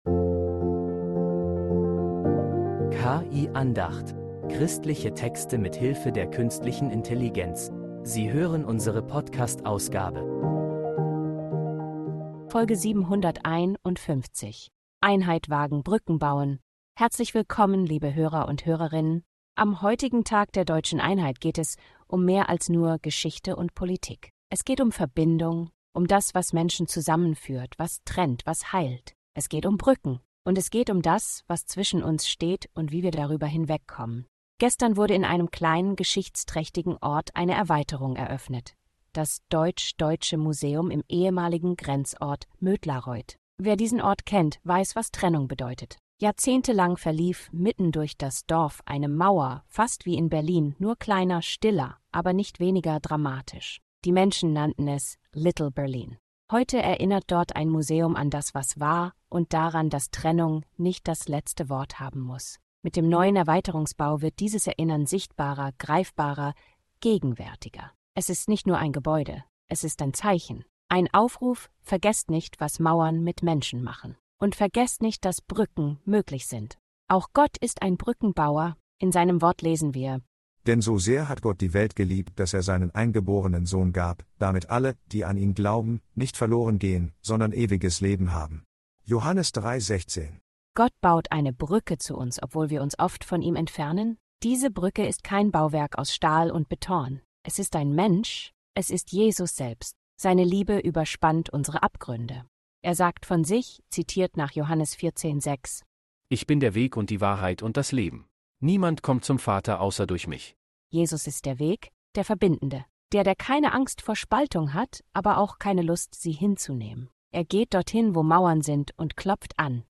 Diese Andacht zum Tag der Deutschen Einheit zeigt, wie Glaube